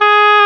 WND OBOE-C.wav